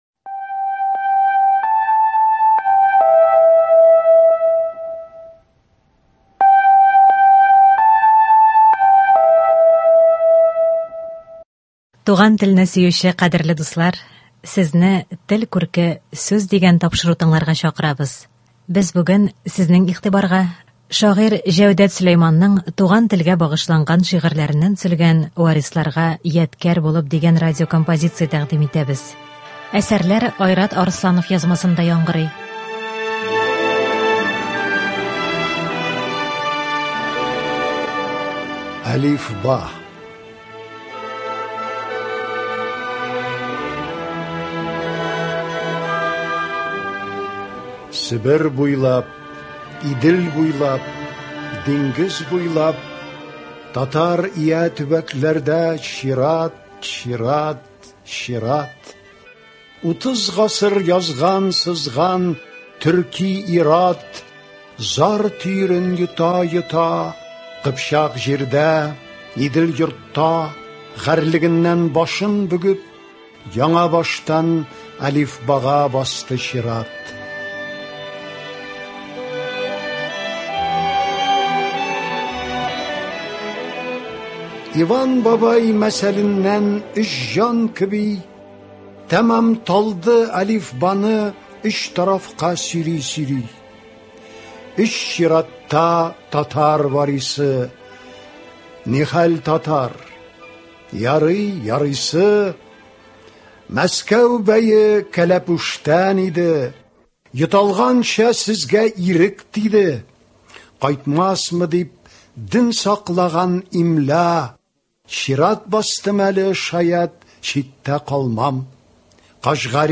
Бүген без сезнең игътибарга шагыйрь Җәүдәт Сөләйманның туган телгә багышланган шигырьләреннән төзелгән “Варисларга ядкәр булып” дигән радиокомпозиция тәкъдим итәбез.